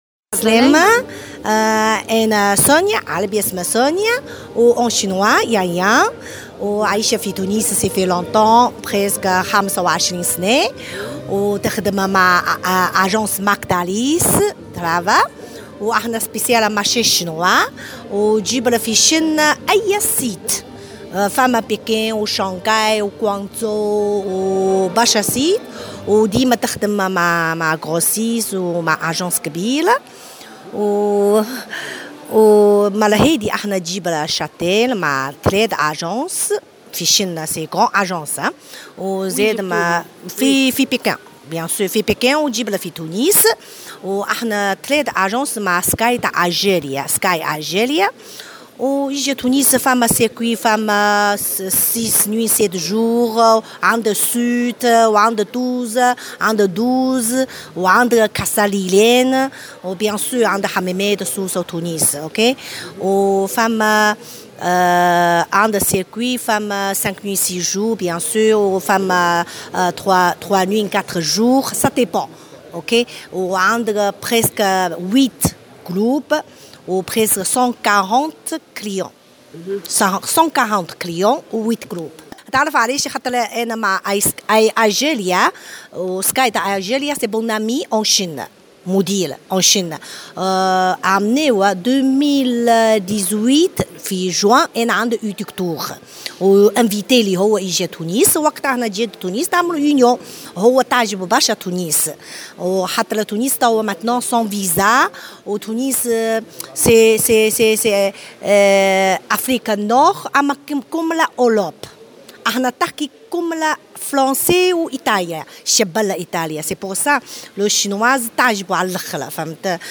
ممثلة وكالة الأسفار الصينية تحميل المشاركة علي